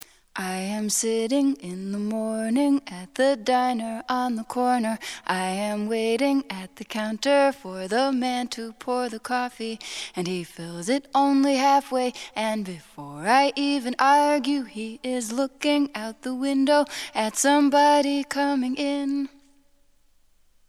Questa tecnica, come già detto, è ottima dal punto di vista qualitativo (all'udito il suono di input e quello di output sono identici) ma la capacità attribuita ai cover è modesta.